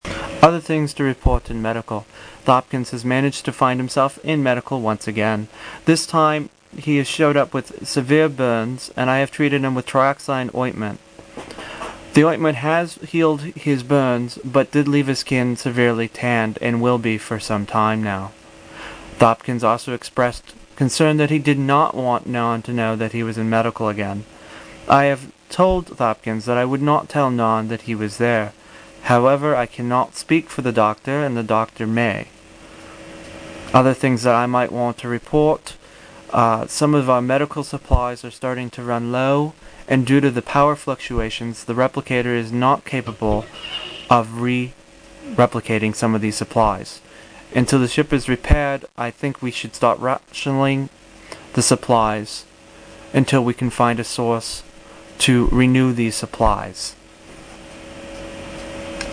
Here you will here some of the voice logs I did for the QOB.